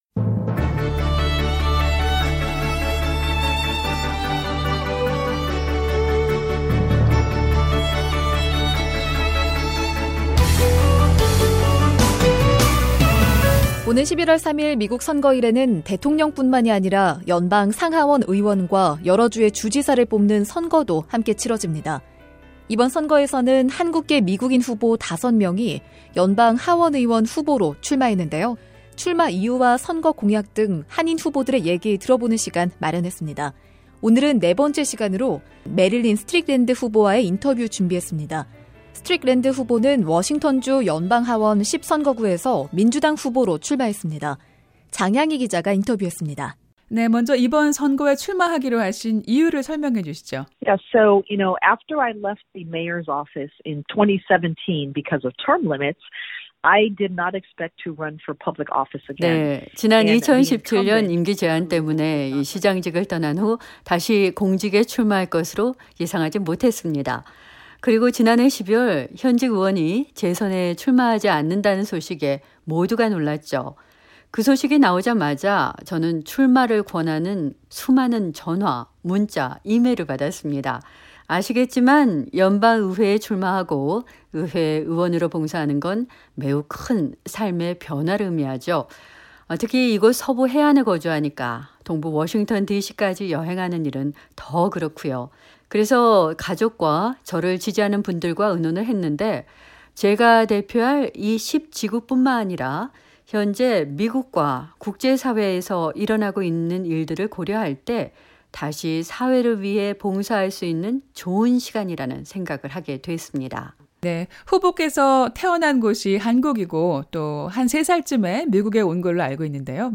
[2020 미국의 선택] 한국계 하원의원 후보 인터뷰 (4) 메릴린 스트릭랜드
출마 이유와 선거 공약 등 한인 후보들의 얘기 들어보는 시간 마련했습니다. 오늘은 네 번째 시간으로, 메릴린 스트릭랜드 후보와의 인터뷰 준비했습니다. 스트릭랜드 후보는 워싱턴주 연방하원 10선거구에서 민주당 후보로 출마했습니다.